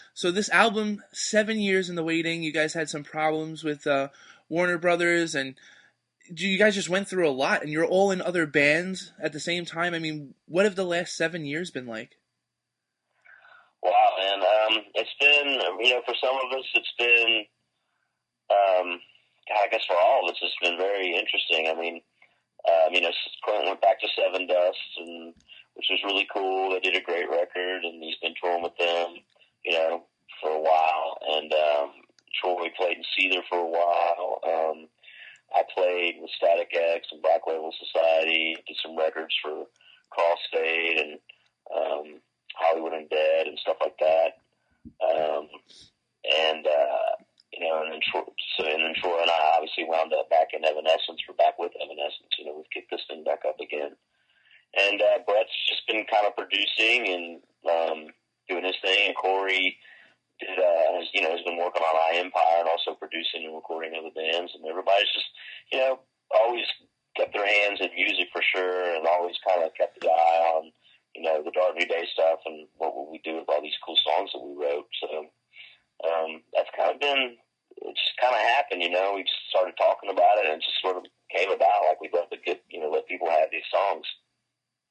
Review Fix Exclusive: Audio Interview with Evanescence and Dark New Day Drummer Will Hunt- Sneak Peek